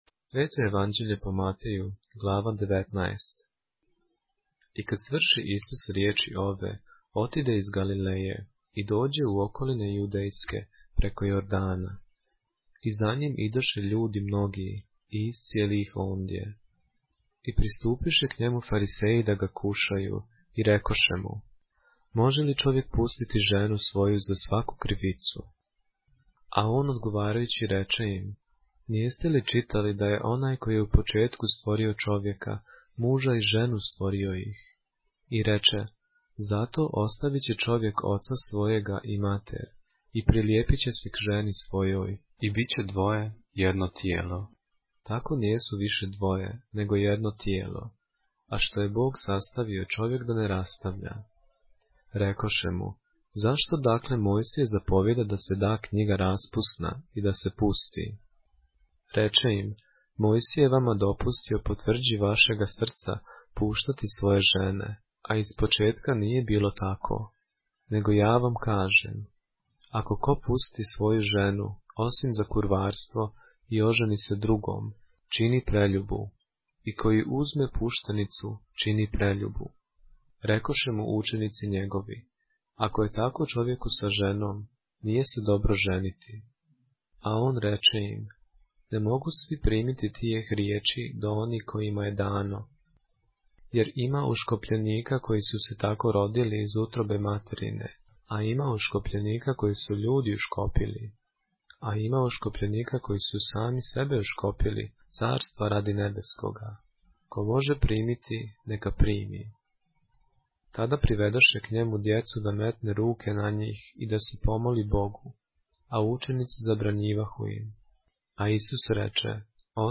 поглавље српске Библије - са аудио нарације - Matthew, chapter 19 of the Holy Bible in the Serbian language